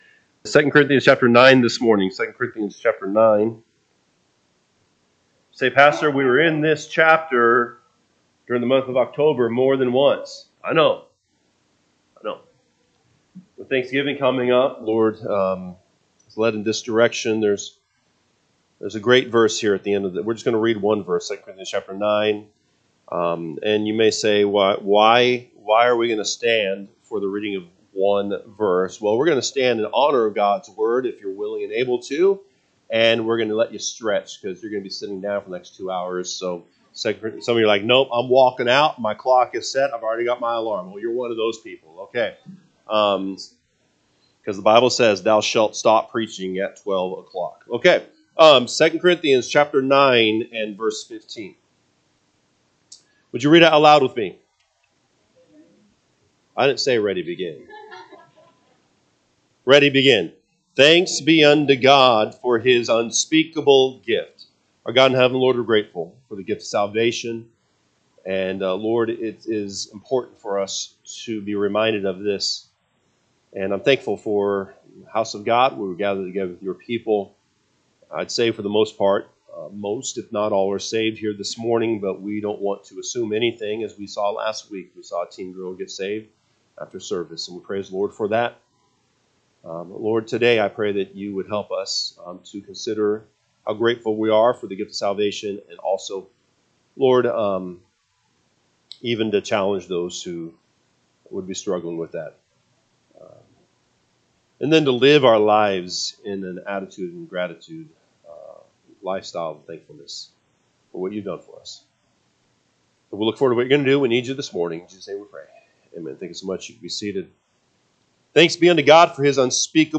November 24 am Service 2 Corinthians 9:15 (KJB) 15 Thanks be unto God for his unspeakable gift.